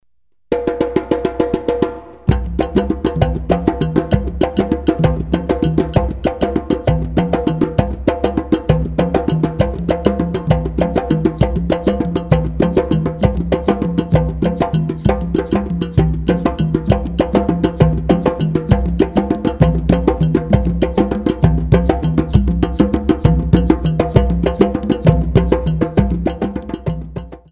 Cd of African rhythms
9 percussion tracks  - some vocals
Based on Traditional djembe rhythm